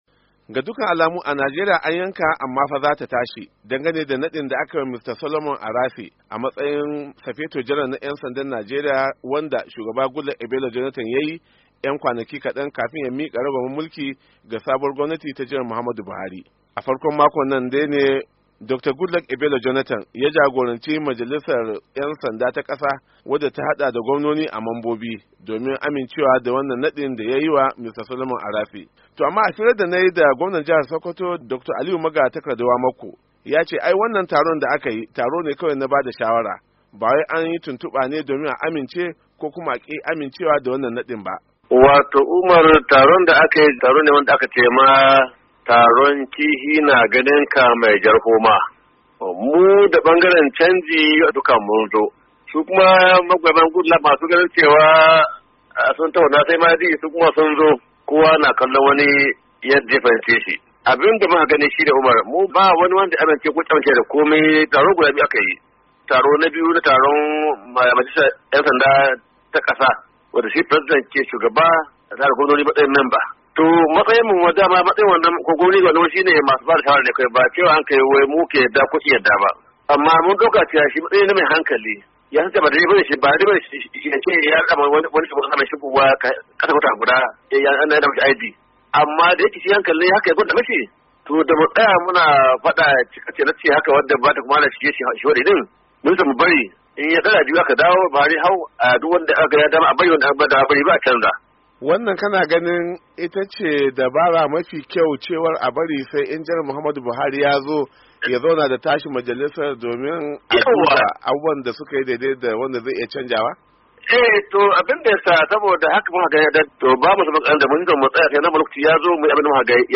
Amma a firar da gwamna Aliyu Wamakko na Sokoto ya yi da wakilin Murya Amurka yace taron da aka yi, wanda ya kasance a wurin, taro ne kawai na bada shawara ba taron tuntuba ba ne na amincewa ko rashin amincewa da Mr. Arase ya zama babban sifeton 'yansanda.